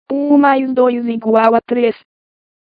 Brasilianisches Portugiesisch
Lernout & Hauspie® TTS3000 TTS engine – Portuguese (Brazil)